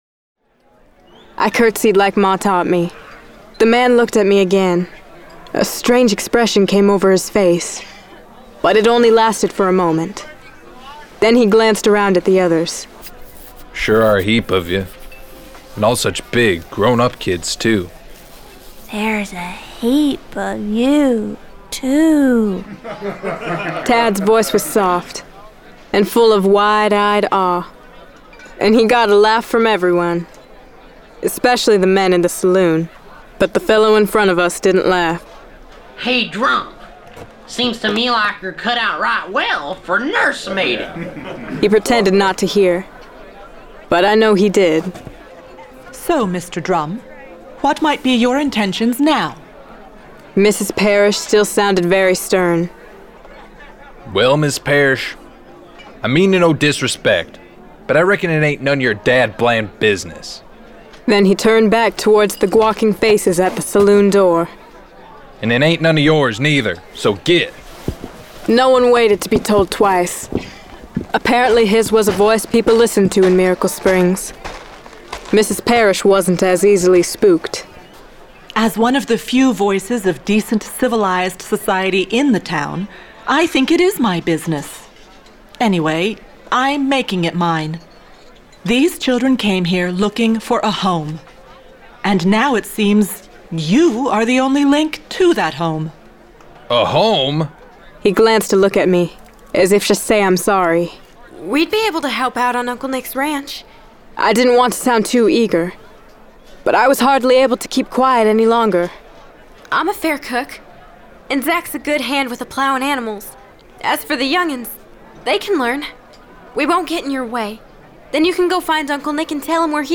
Discover the audio drama based on the bestselling series, The Journals of Corrie Belle Hollister, by Michael Phillips and Judith Pella.